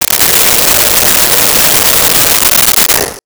Blow Dryer 02
Blow Dryer 02.wav